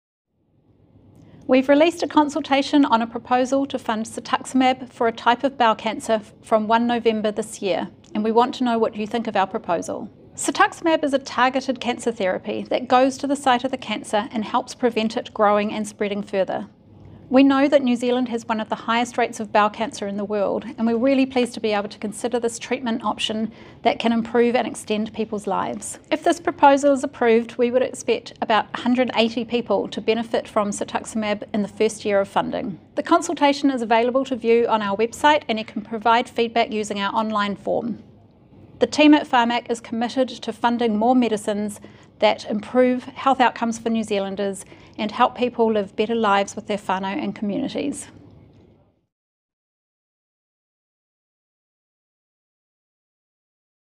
• cetuximab – seh-tuk-sih-mab